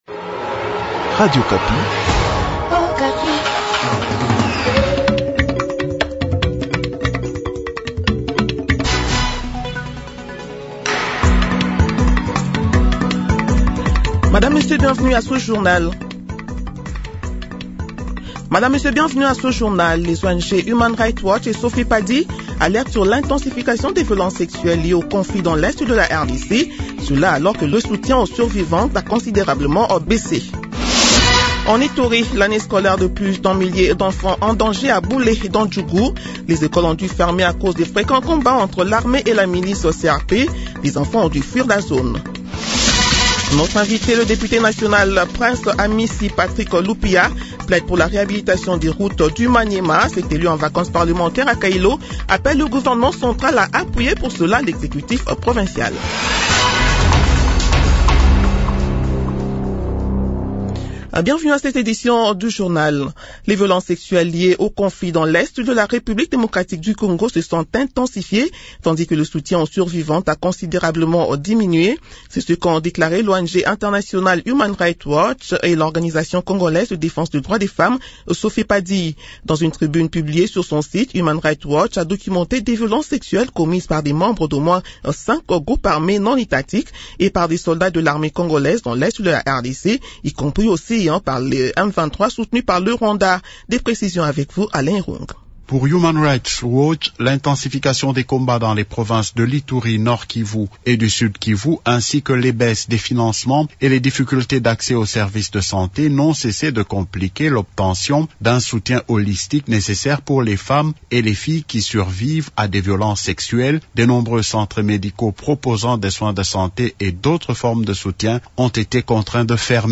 Journal Midi du Jeudi 15 jjanvier 2026